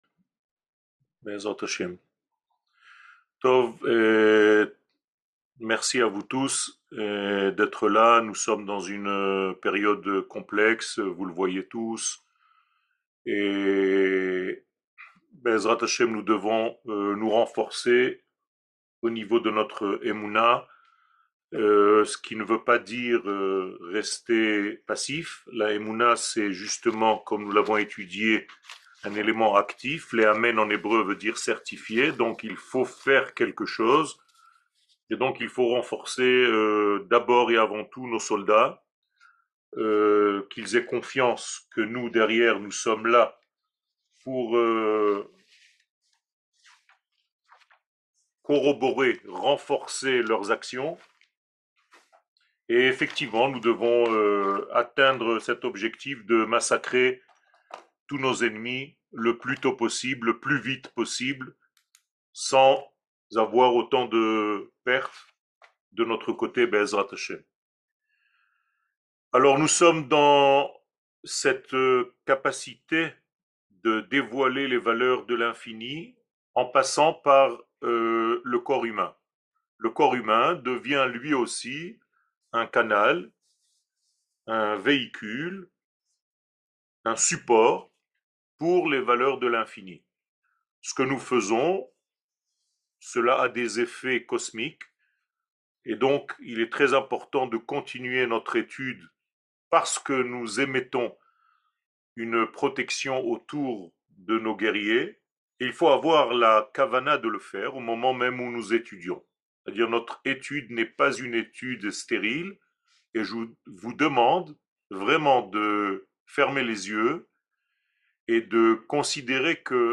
שיעורים, הרצאות